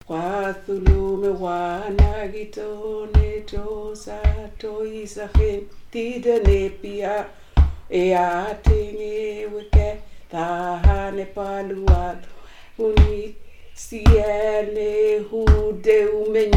Enquête avec enregistrements sonores
Chants enfantins kanaks
Pièce musicale inédite